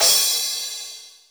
Index of /90_sSampleCDs/AKAI S6000 CD-ROM - Volume 3/Drum_Kit/AMBIENCE_KIT3
CRASH CS1 -S.WAV